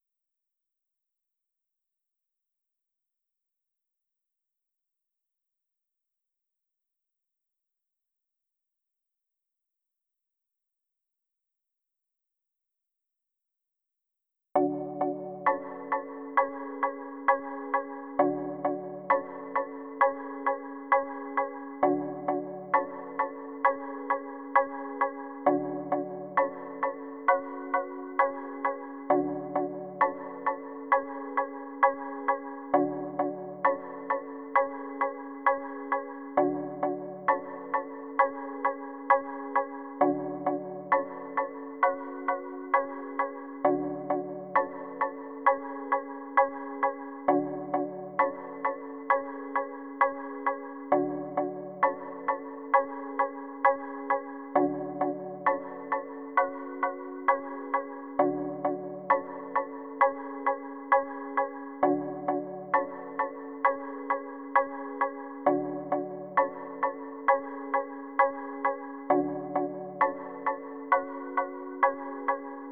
Drive Thru Plucks.wav